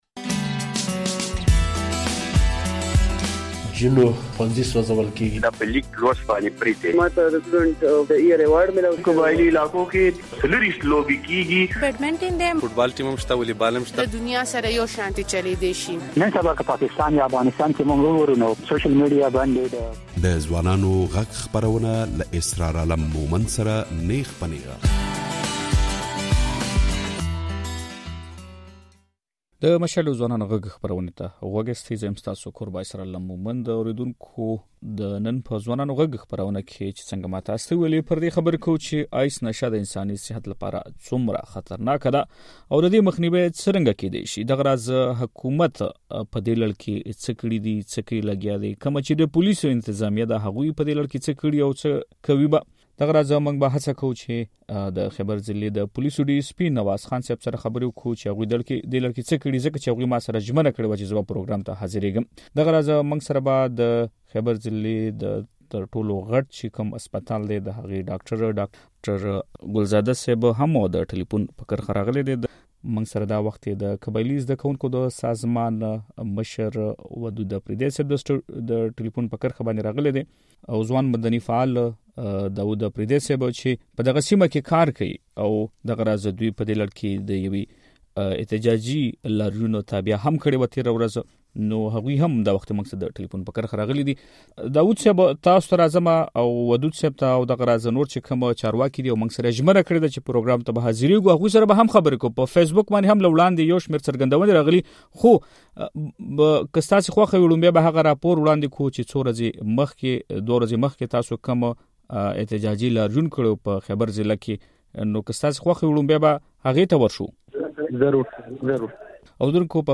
د مشال راډيو په ځوانانو غږ خپرونه کې د خېبر ضلعې ځوانان، مدني فعالان او پوليس چارواکي وايي چې په دې سيمه کې د ايس په نوم نشه ډېره زياته شوې او ځينې زده کوونکي او ماشومان هم په دې نشه روږد دي.